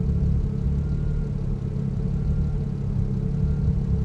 rr3-assets/files/.depot/audio/Vehicles/v12_06/v12_06_idle.wav
v12_06_idle.wav